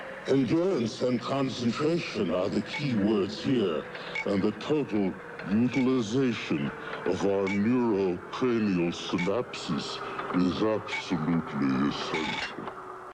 A fastidious representative of his species, Dromboid spoke with an even and emotionless keel, yet purported to appreciate beauty.